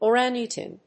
/əˈræŋəˌtæŋ(米国英語)/